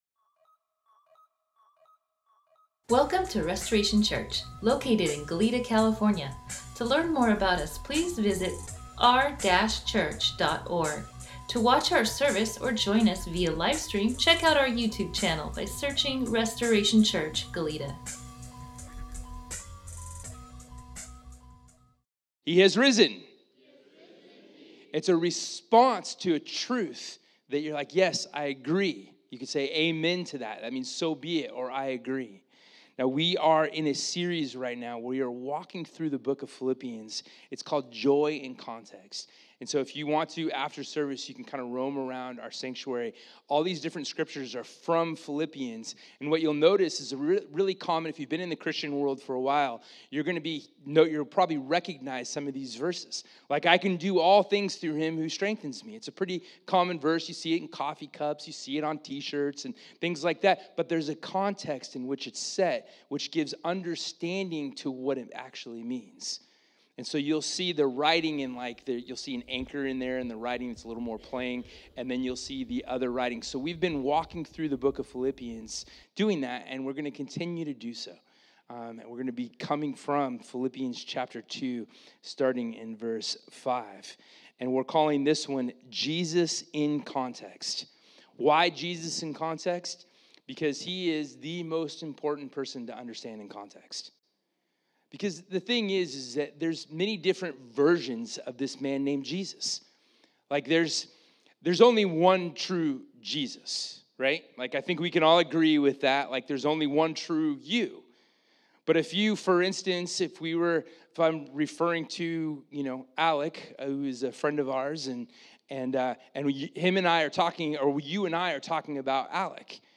Sermon NotesDownload HAPPY EASTER!!!